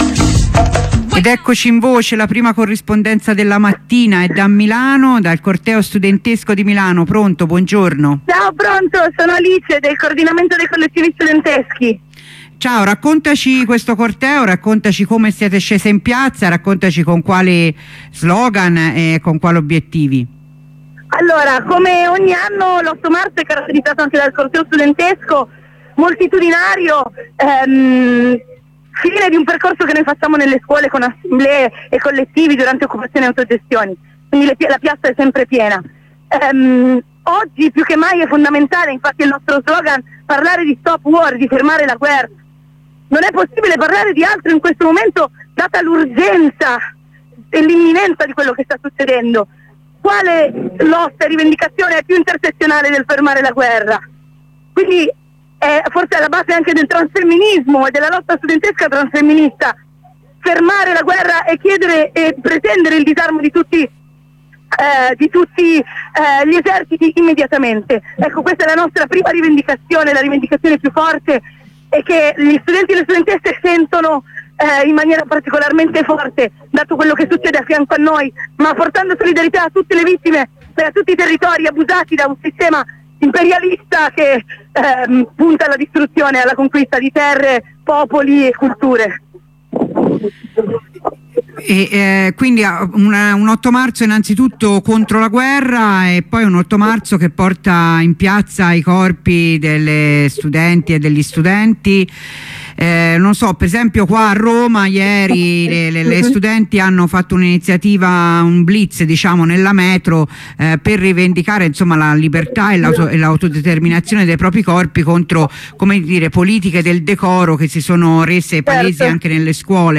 Corrispondenza con una compagna di Non una di meno Torino